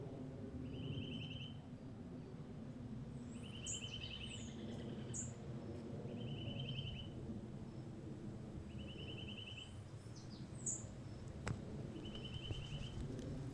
Flat-billed Vireo (Vireo nanus)
Life Stage: Adult
Location or protected area: Parque Nacional Cotubamana
Condition: Wild
Certainty: Photographed, Recorded vocal